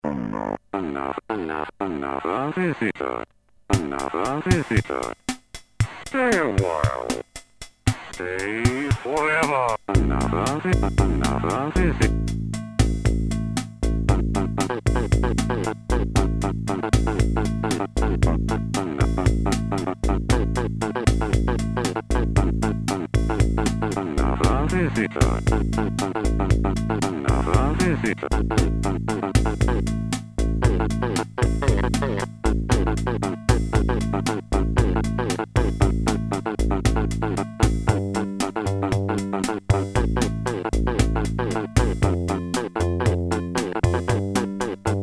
editSPEECH PROCESSED BY OTHER MACHINE I recorded some fun variations about the "another visitor" speech processed by different samplers, effects, vocoders or whatever!
Sampling + fm music Yamaha VSS-100